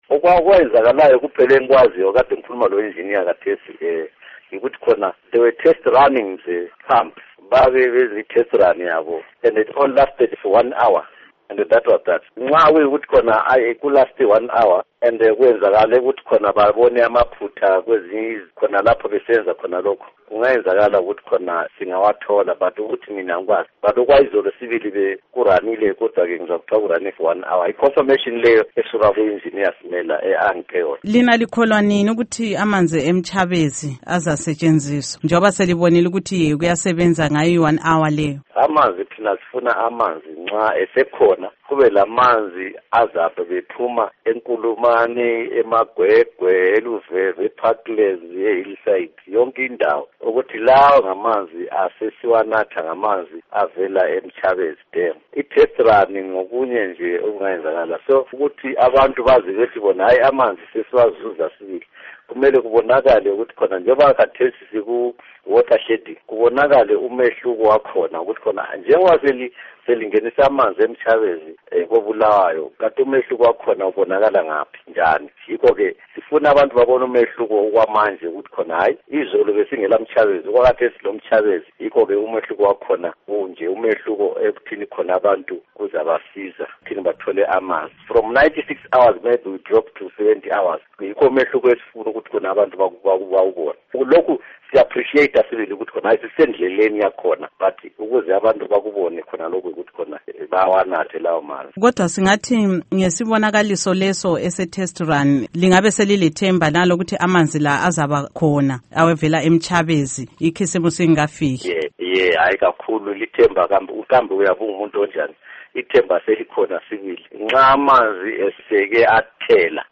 Ingxoxo LoKhansila Patrick Thaba-Moyo